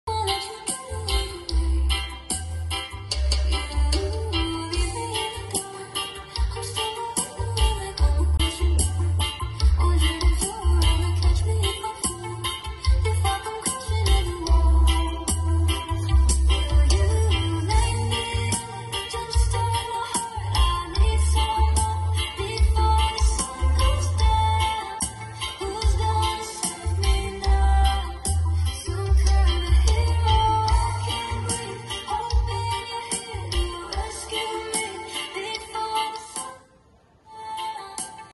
Check Sound Ashley Line aray sound effects free download